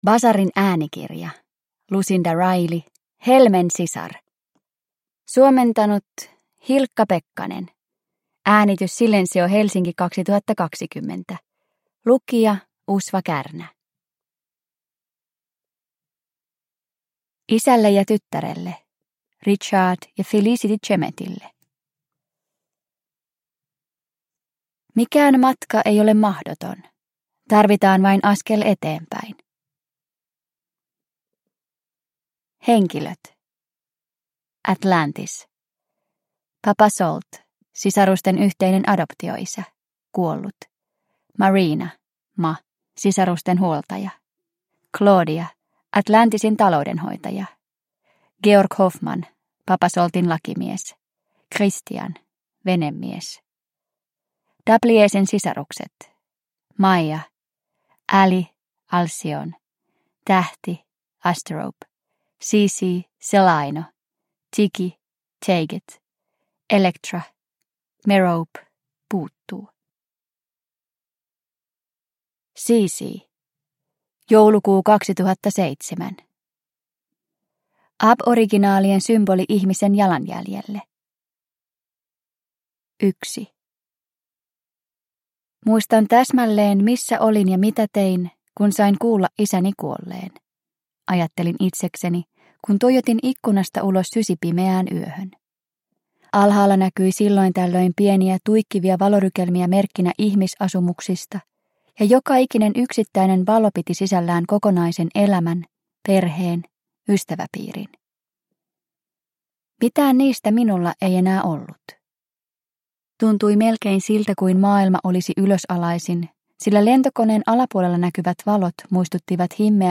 Helmen sisar – Ljudbok – Laddas ner